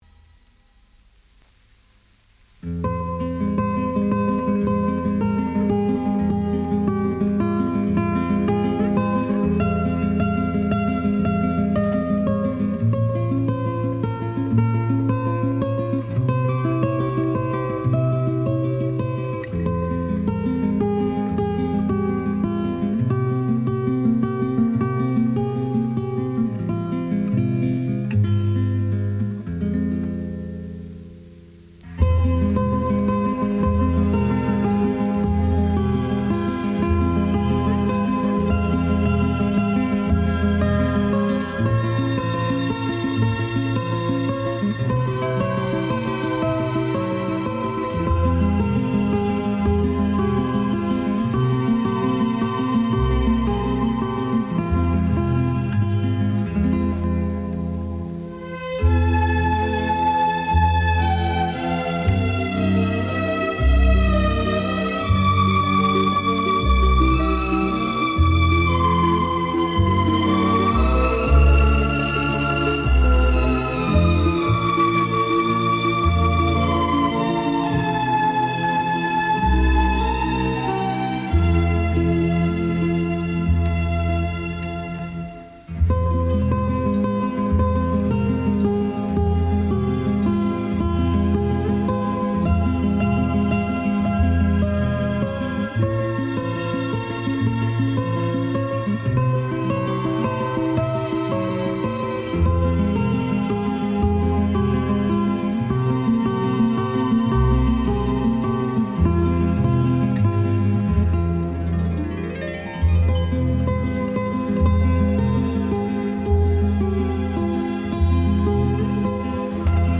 현악기를 중심으로 하는 유려한 연주로 인기를 모았고 무드음악계의 제1인자로 알려졌다.